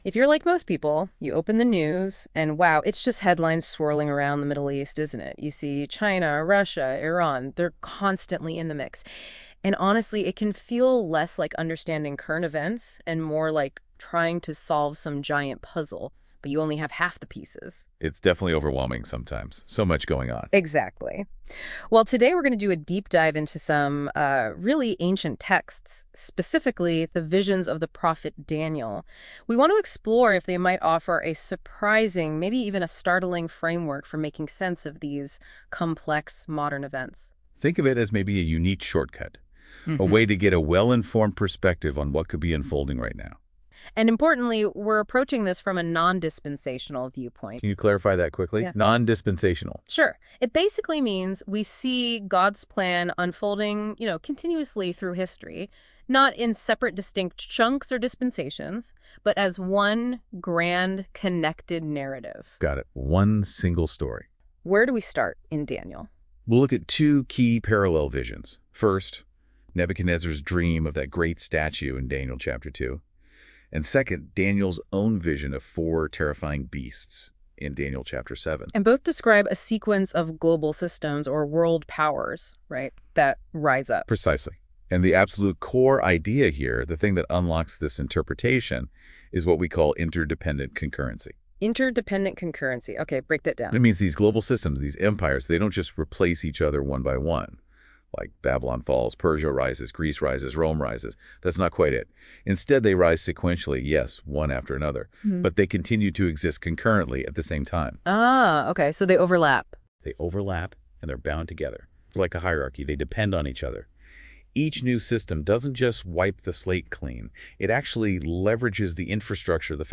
Click To Listen To The Podcast Audio generated by NotebookLM